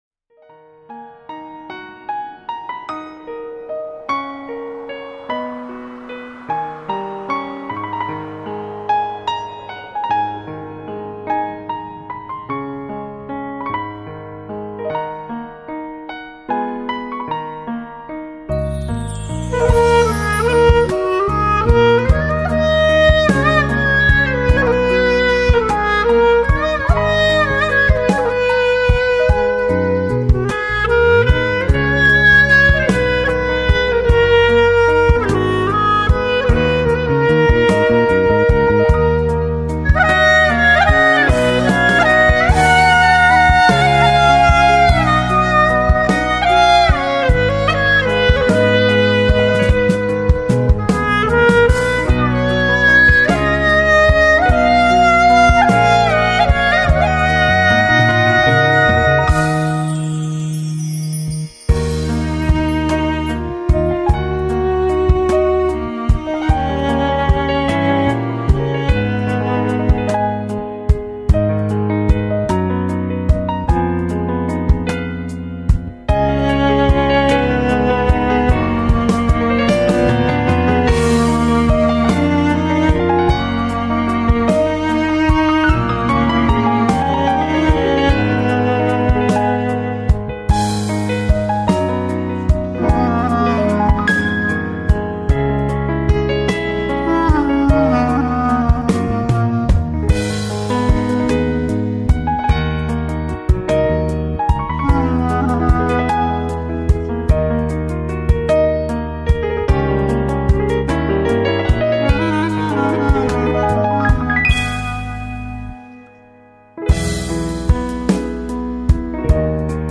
ساز زنده:کلارینت
غمگین